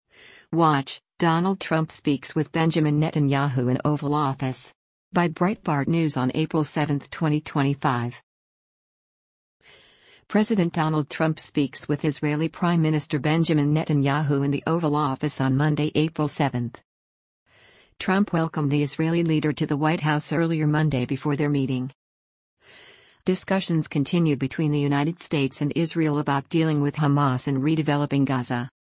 President Donald Trump speaks with Israeli Prime Minister Benjamin Netanyahu in the Oval Office on Monday, April 7.